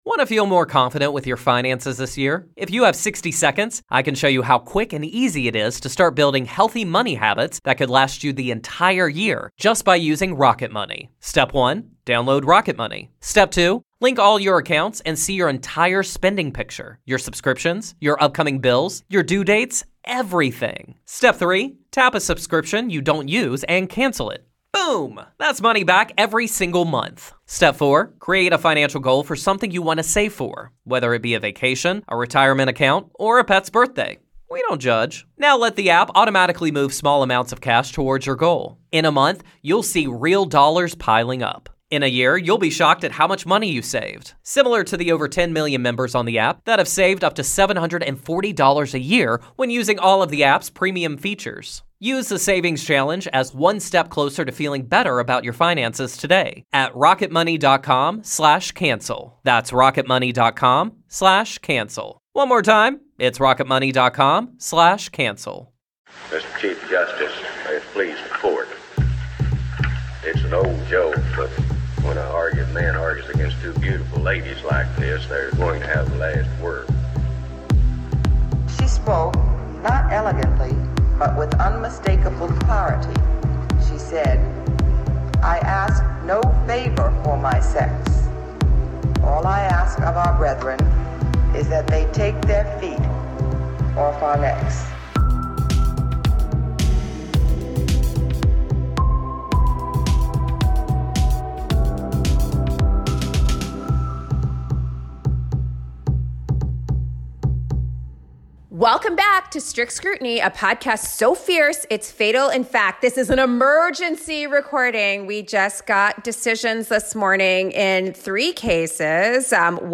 *airhorn* The Supreme Court released the opinion in June Medical Services v. Russo-- this term's big abortion case.